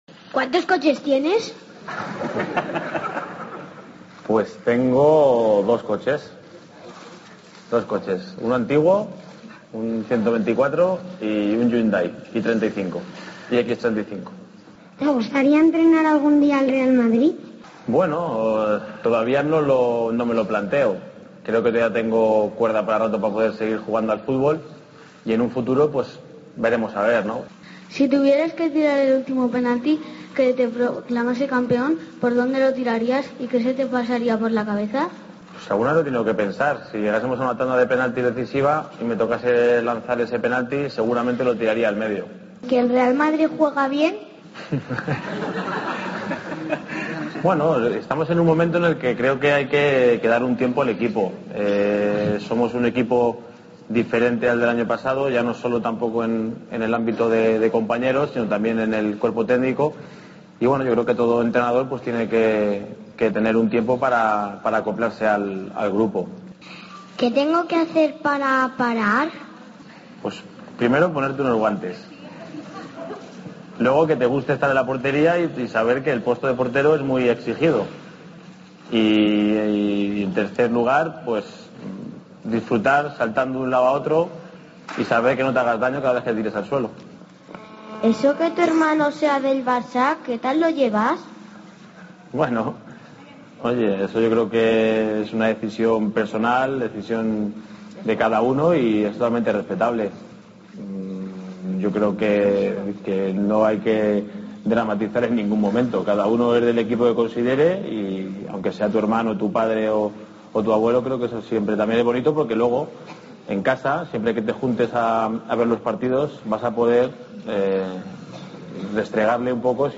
AUDIO: El capitán del Real Madrid, Íker Casillas, respondió a las preguntas que le formularon un grupo de niños.